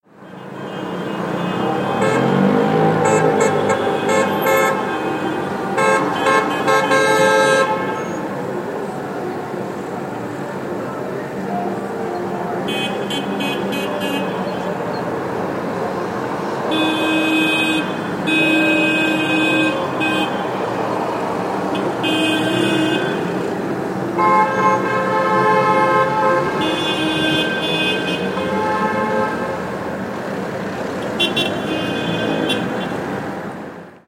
جلوه های صوتی
دانلود صدای بوق بوق ماشین ها در خیابان از ساعد نیوز با لینک مستقیم و کیفیت بالا
برچسب: دانلود آهنگ های افکت صوتی حمل و نقل